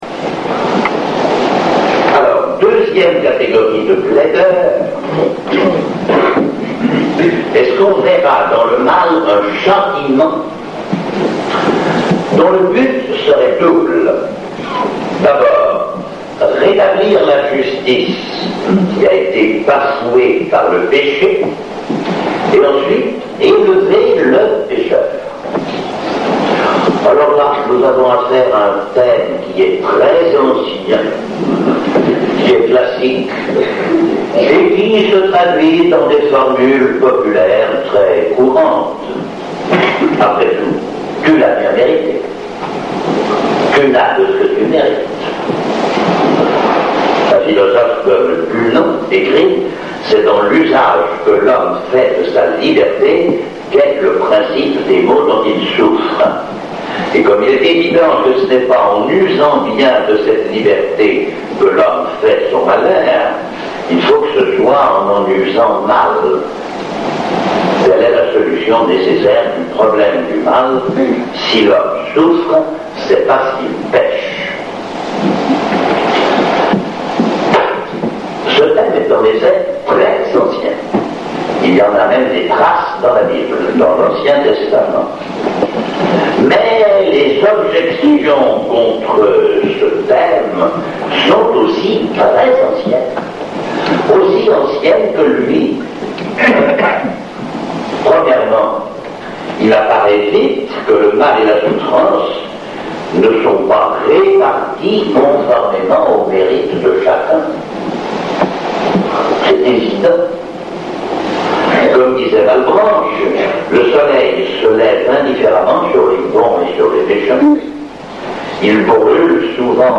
Conférence du Père François Varillon sur le problème du mal, suite (3/5)